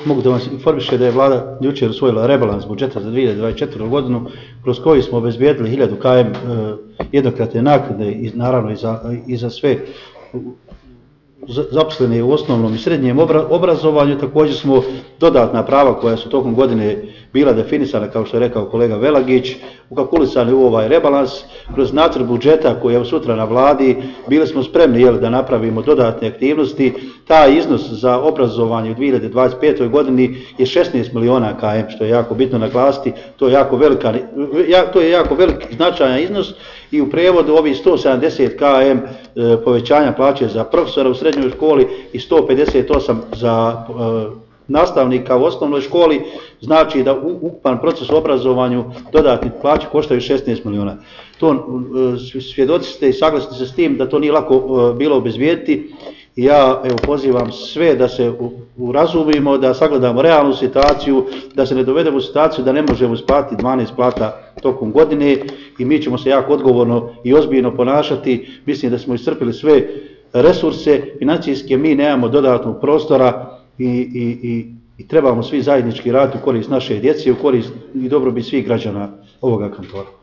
Cjeloviti zvučni zapisi s konferencije za novine na kojoj su se, uz predsjednicu Buhač, javnosti obratili i ministri Velagić i Šuta, dostupni su u privitku.